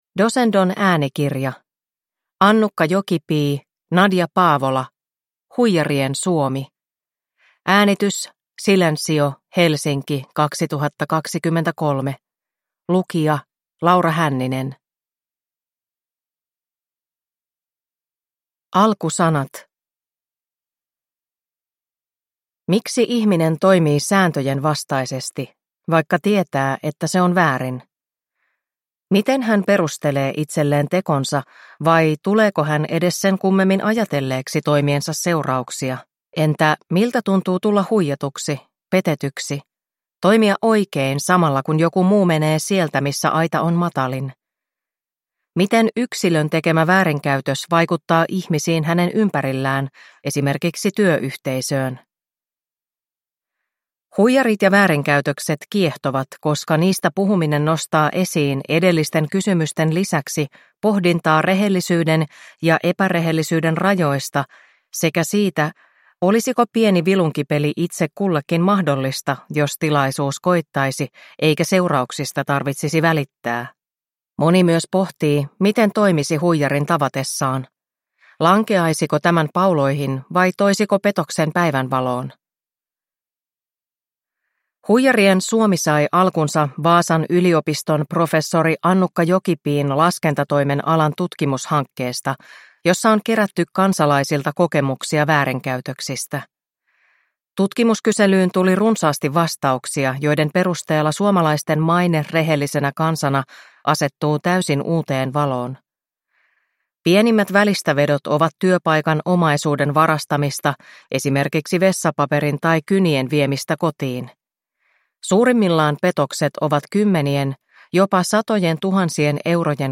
Huijarien Suomi – Ljudbok – Laddas ner